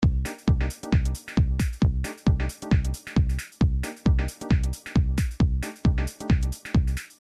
House Goa Euphoria Garage